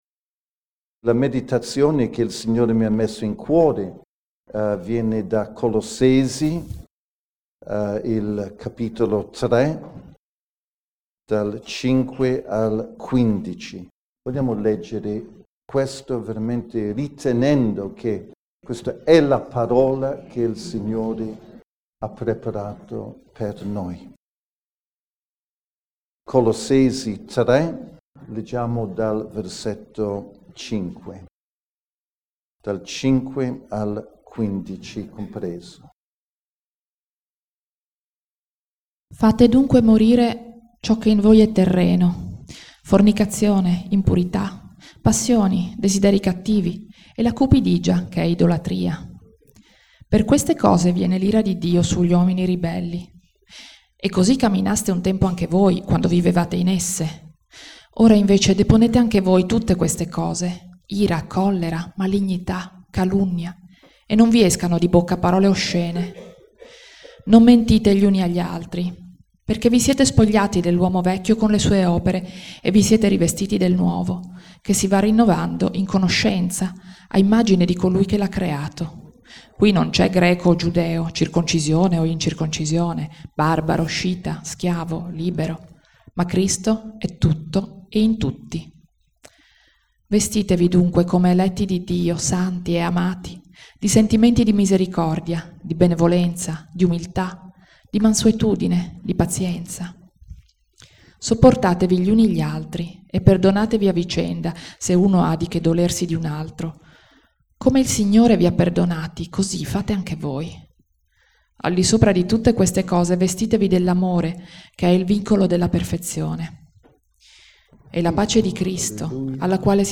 Predicazione 30 novembre 2014 - Rivestiamoci, come eletti di Dio, di ci� che Egli �